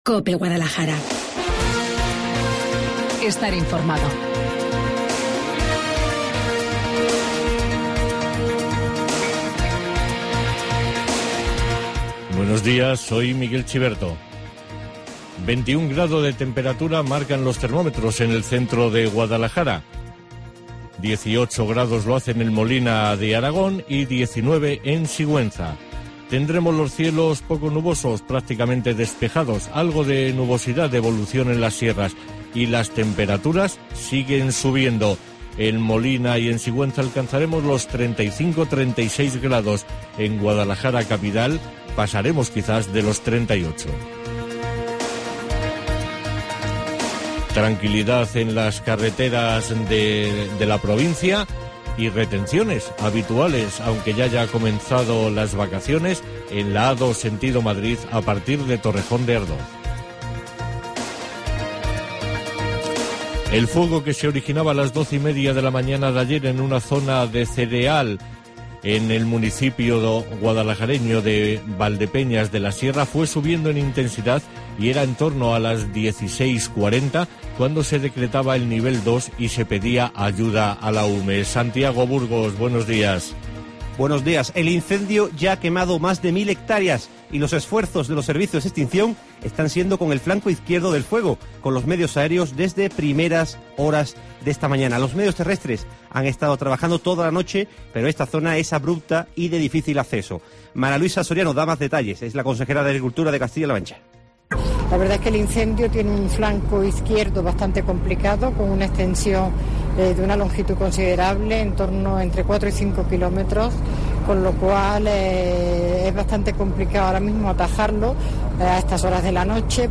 Informativo Guadalajara 1 DE AGOSTO